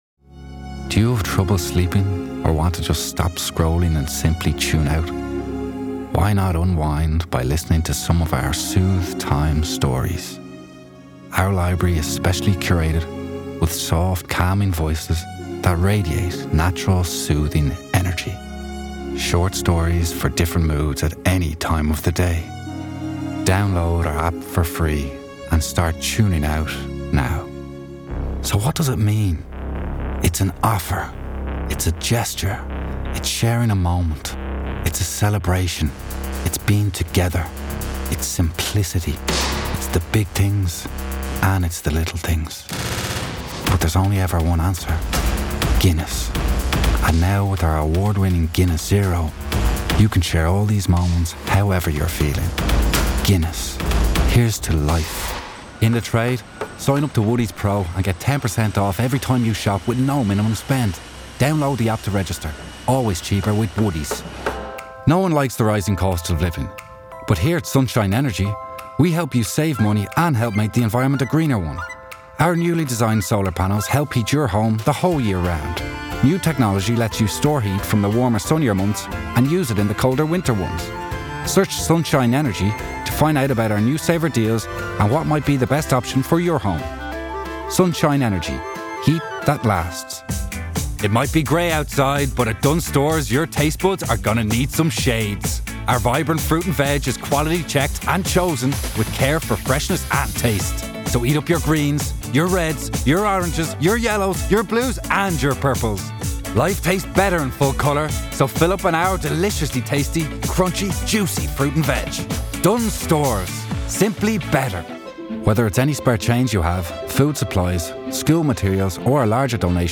Male
Rode Mic for self tapes
20s/30s, 30s/40s
Irish Dublin Neutral, Irish Neutral